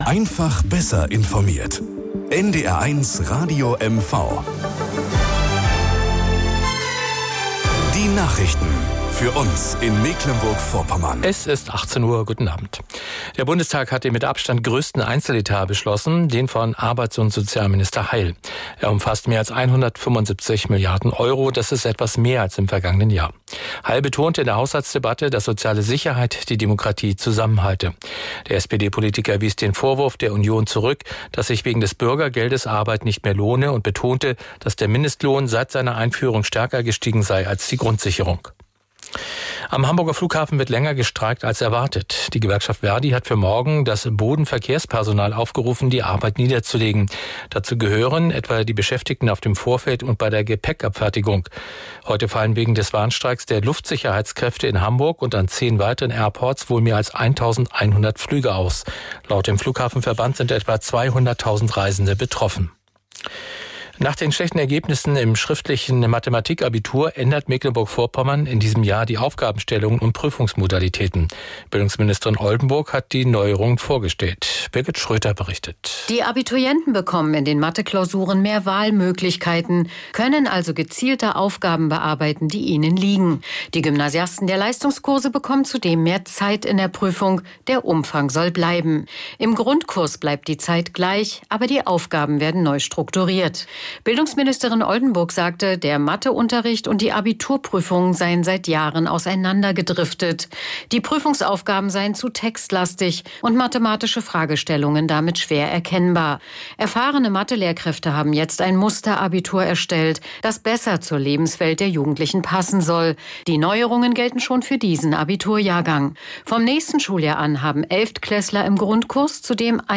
Morgenandacht bei NDR 1 Radio MV - 02.02.2024